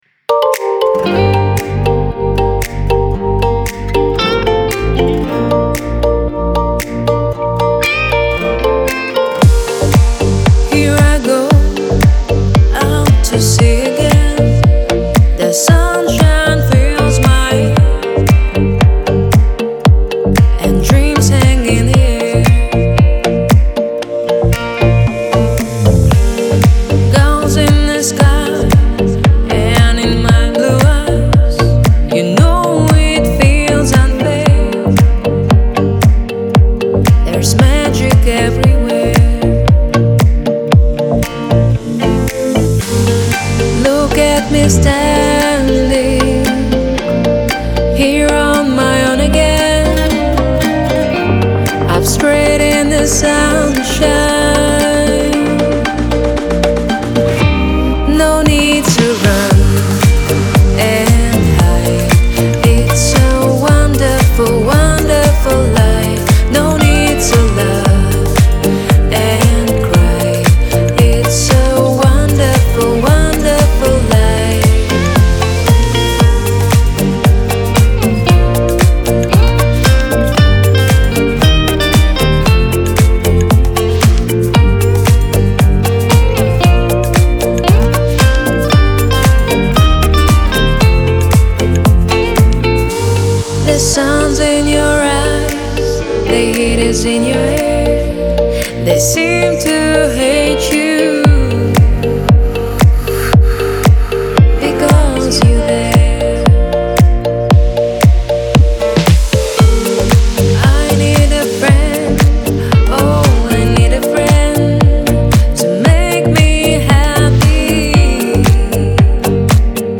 диско
танцевальная музыка
dance